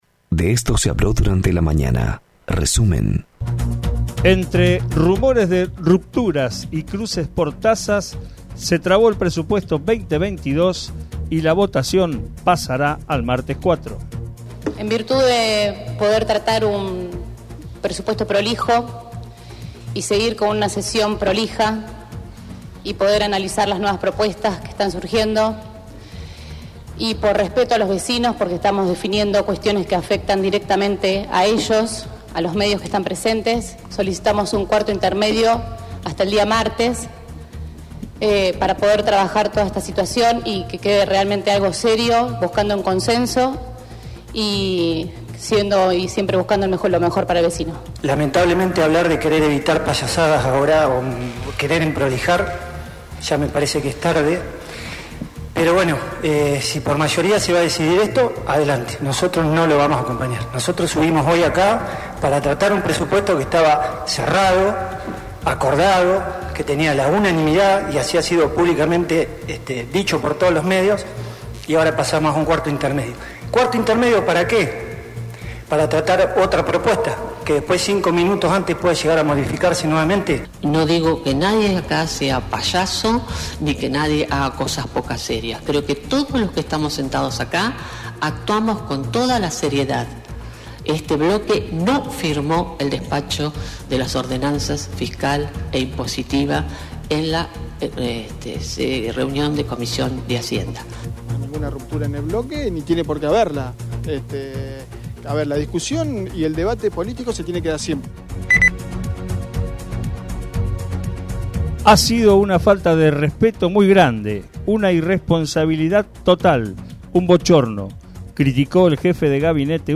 Escuchá los principales temas noticiosos de la mañana en nuestro resumen de Radio 3 95.7.